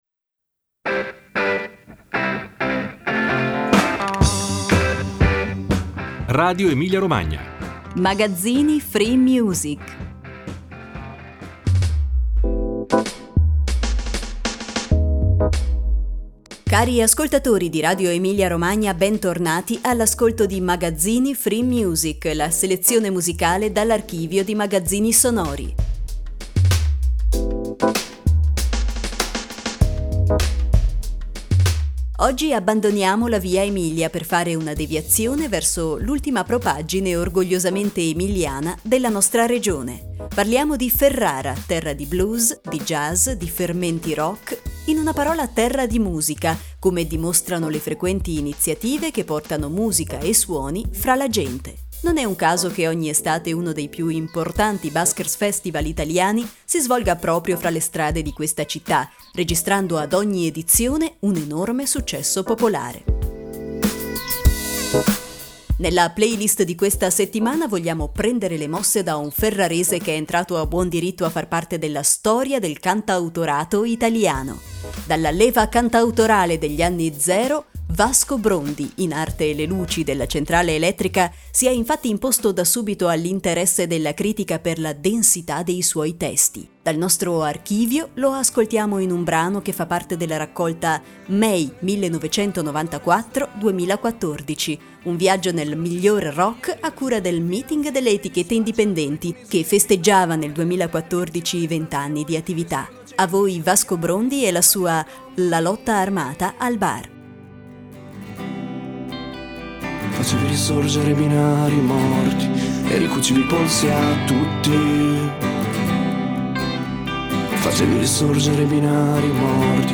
Cari ascoltatori di RadioEmiliaRomagna, bentornati all'ascolto di Magazzini FreeMusic, la selezione musicale dall'archivio di Magazzini Sonori.